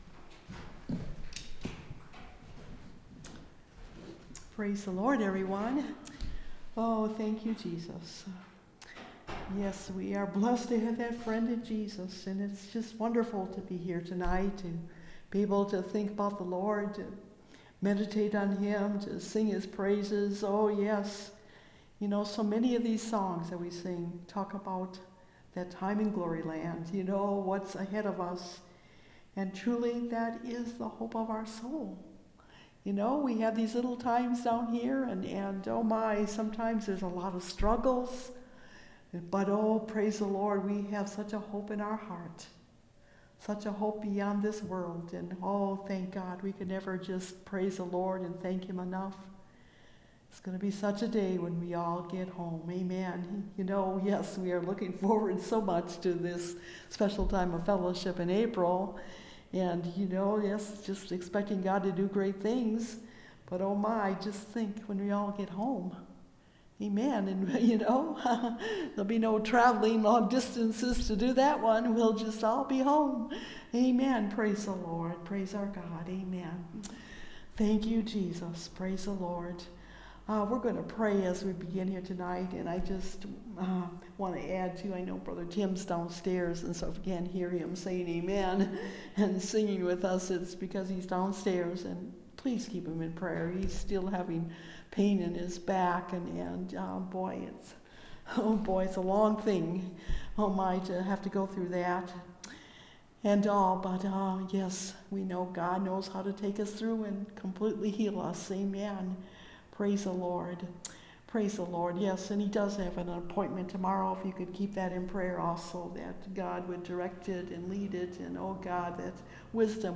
Service Type: Wednesday Night Bible Study